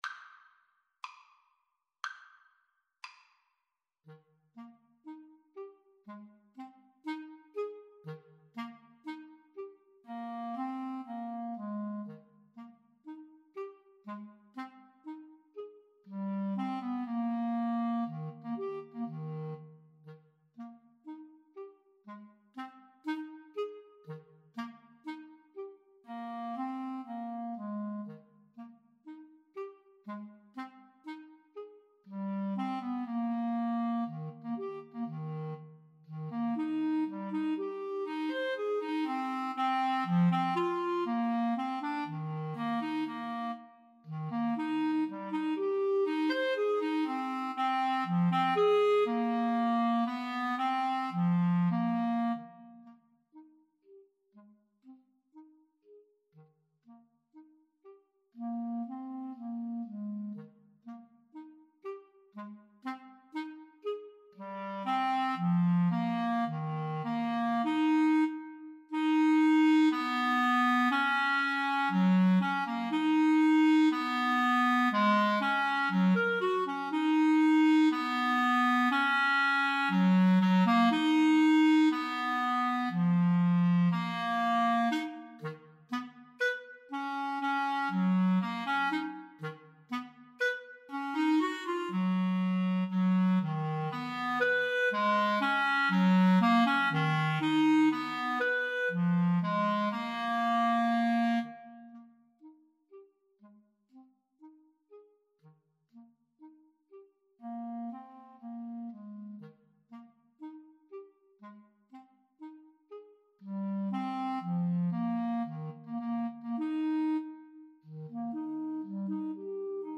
Free Sheet music for Clarinet Duet
Eb major (Sounding Pitch) F major (Clarinet in Bb) (View more Eb major Music for Clarinet Duet )
2/4 (View more 2/4 Music)
= 60 Poco lento e grazioso
Clarinet Duet  (View more Intermediate Clarinet Duet Music)
Classical (View more Classical Clarinet Duet Music)